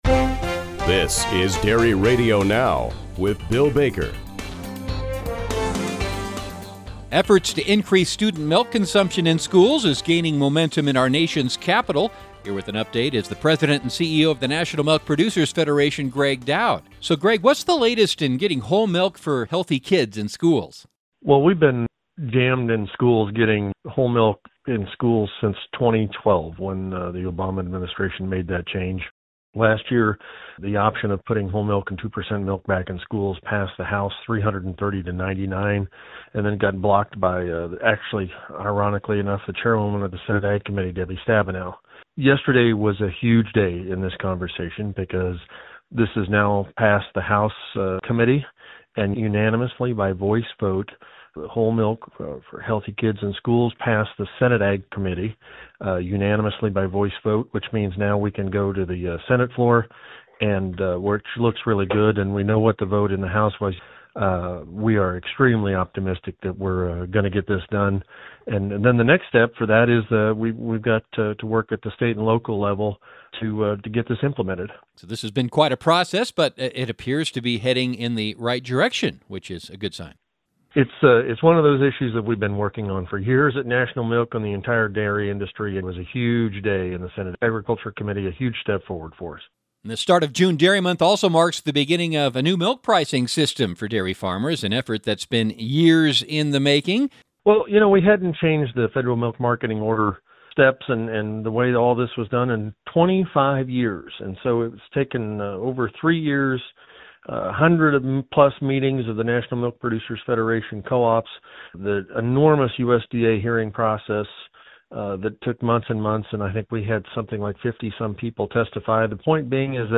As June Dairy Month begins, NMPF President & CEO Gregg Doud explains for listeners of Dairy Radio Now that NMPF has helped notch two important achievements for the dairy community: the implementation of long-awaited updates to the Federal Order system, and passage by the Senate Agriculture Committee of legislation to expand milk choices in schools.